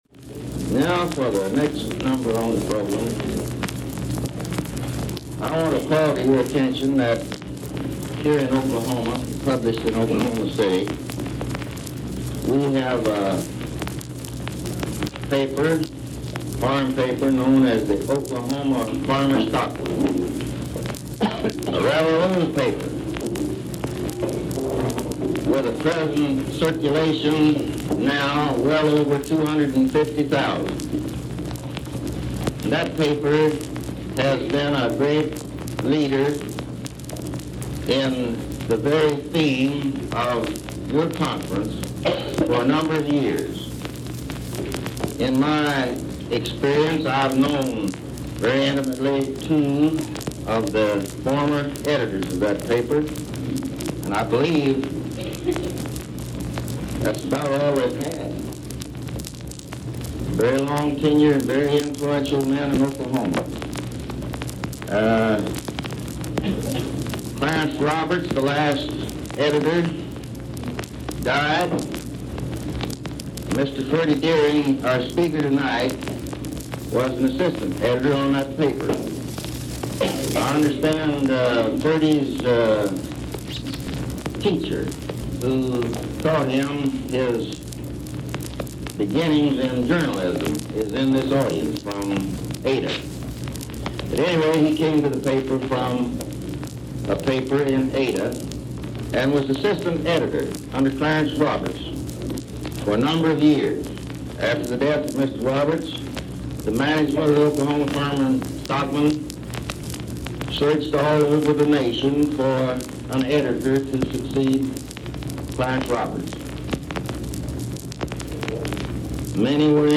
SPEECH: Midwest Conference on Rural Life and Education (Text) - Audio Recording (25 Minutes) This speech was recorded on four 78 RPM records, so some parts are better quality than others, and there are some gaps.